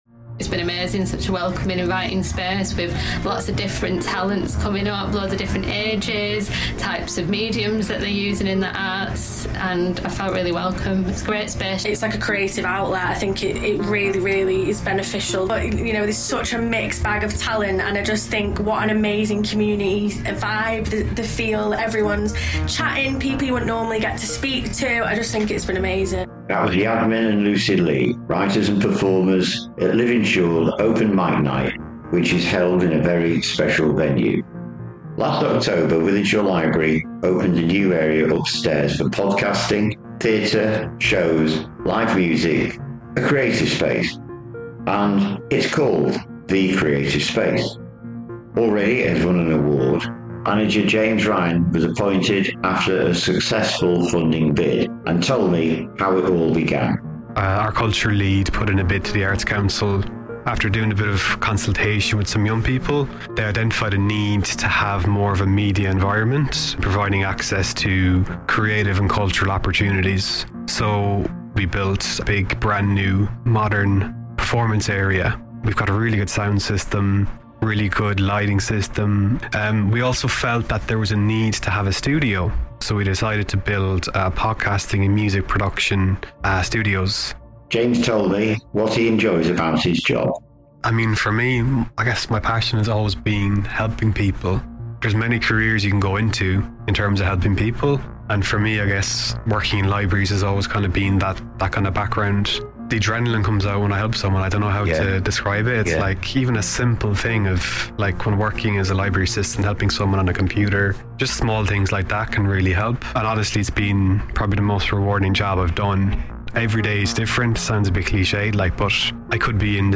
Wythenshawe Creative Space Interview